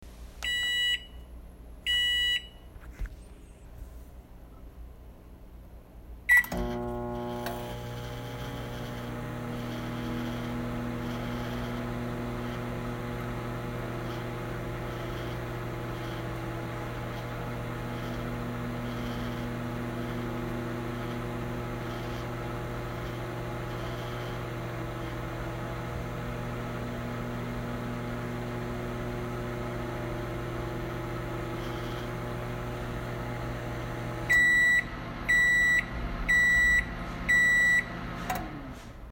field recording two
The kitchen of Salem House, 1 AM.
The beeping of the buttons on the microwave and the turning and humming of the microwave.
Microwave-Sounds-1.mp3